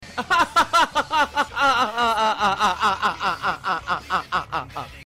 Laugh original sound button